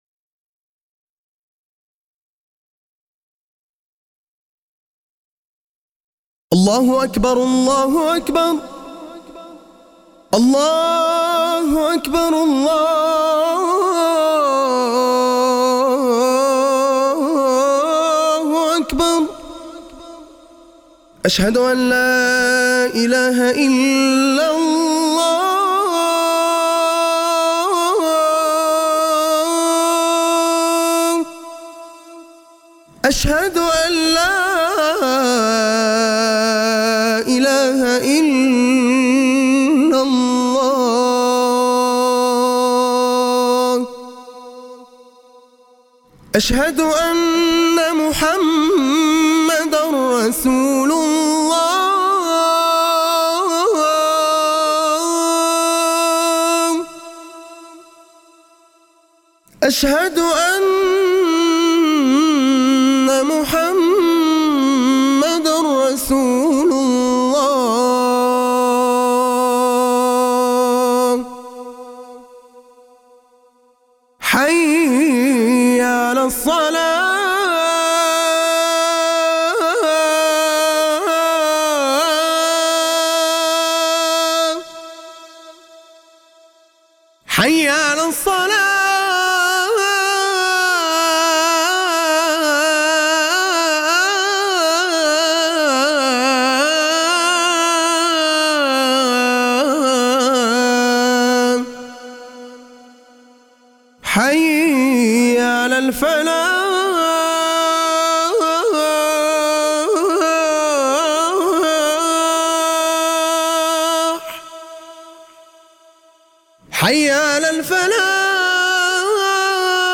اذان-الفجر-1.mp3